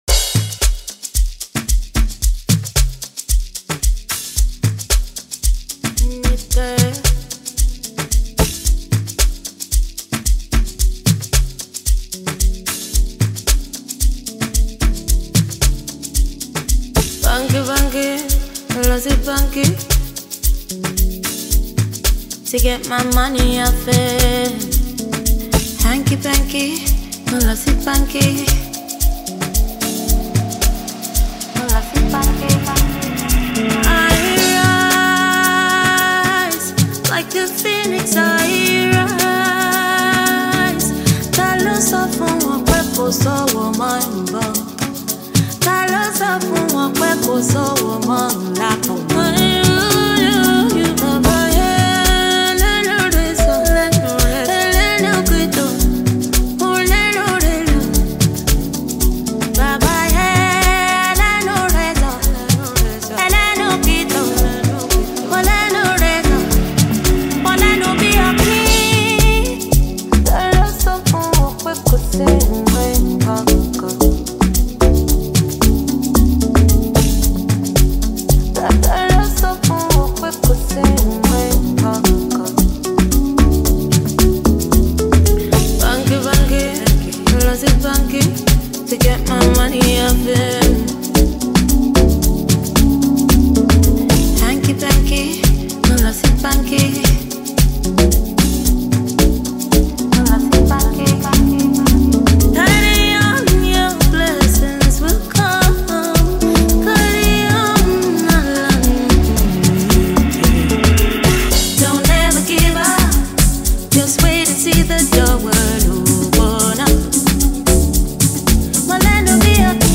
silky smooth Piano production